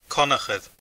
Pronunciation: [ˈkʰɔnəxəɾʲ]